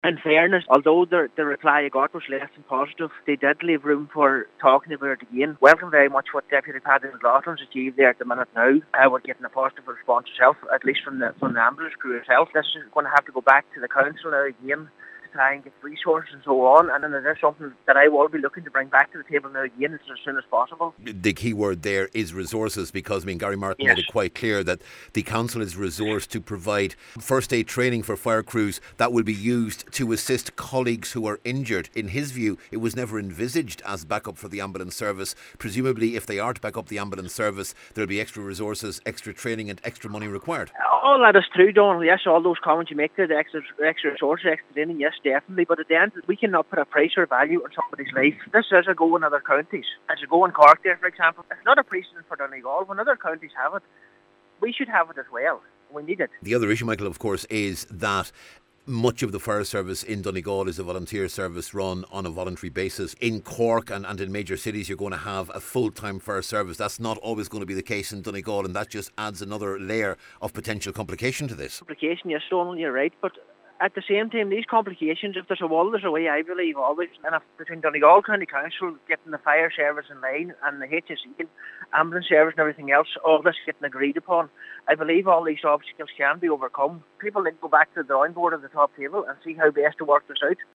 Cllr McClafferty has confirmed he’ll be raising the issue again, saying the council, HSE and government should work together to address the issue of resources: